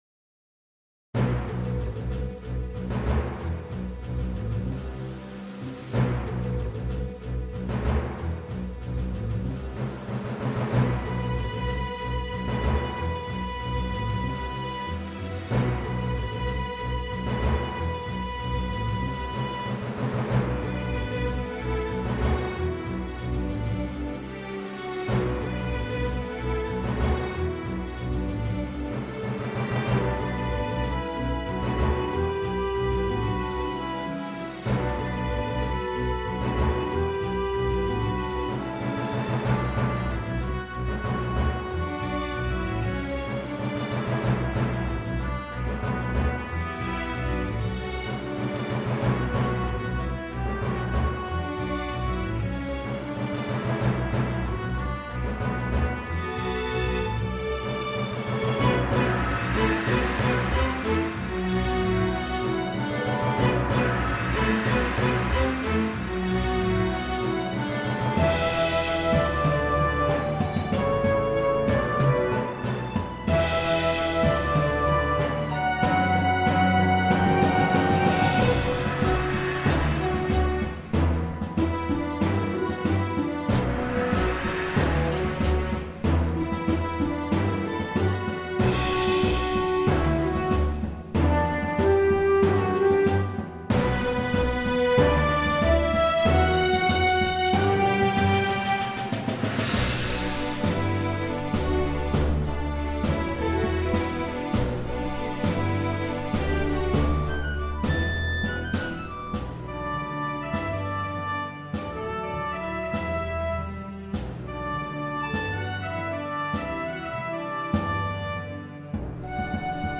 Making an orchestral version of that song isn't easy at all!